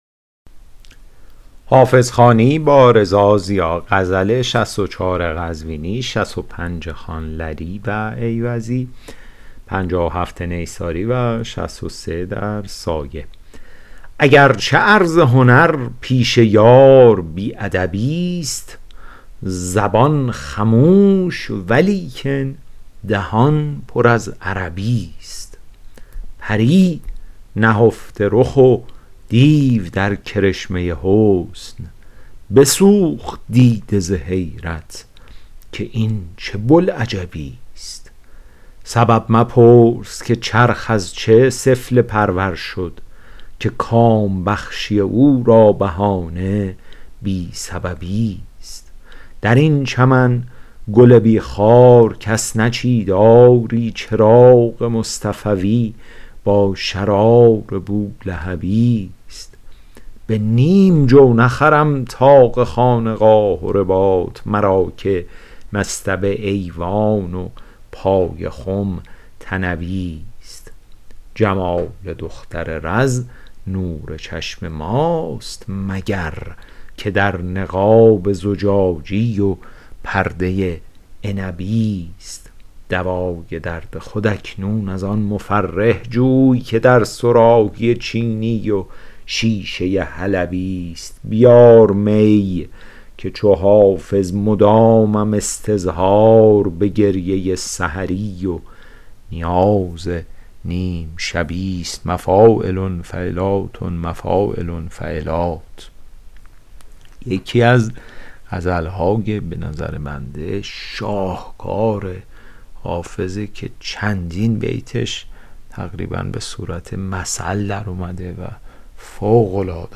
شرح صوتی غزل شمارهٔ ۶۴